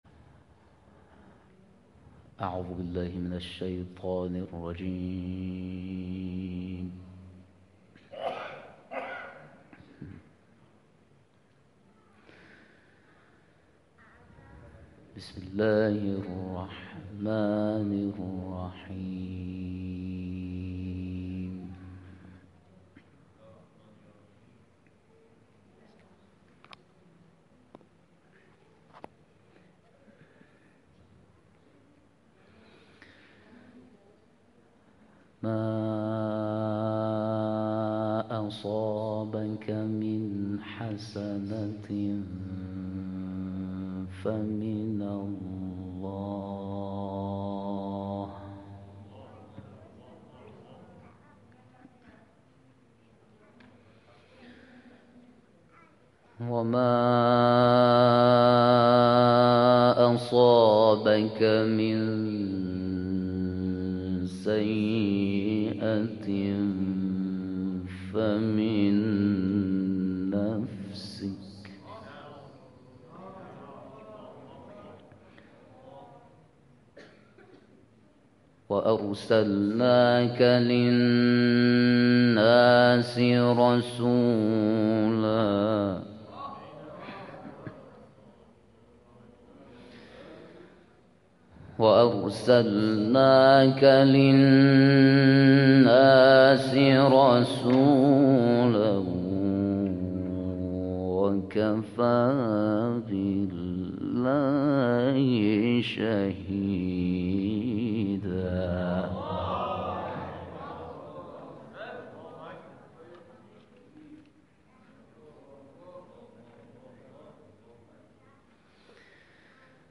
در محفل مسجد احباب‌الحسین(ع)